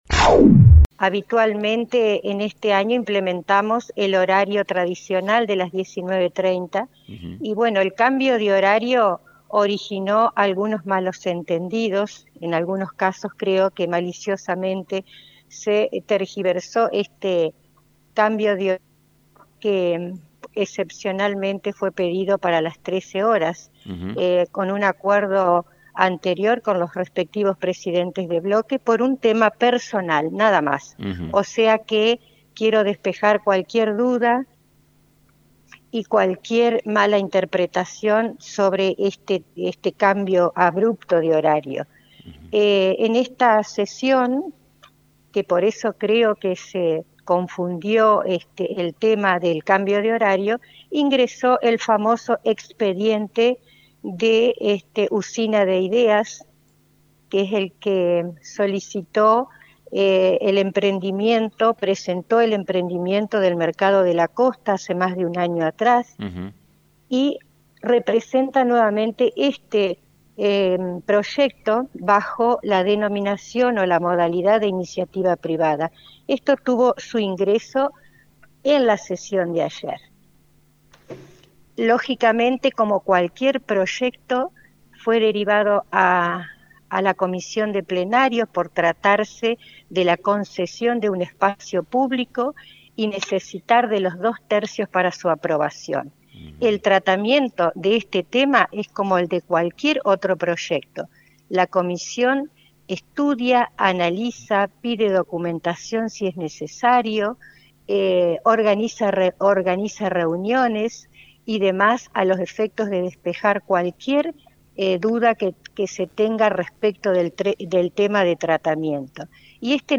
En diálogo con FM 90.3, la viceintendenta Ana Schuth comentó sobre un proyecto que ingresó al Concejo Deliberante y que generó gran polémica en la comunidad. Este proyecto propone concesionar el espacio público del pie de la colina, desde calle Güemes 350 metros en dirección al Solar.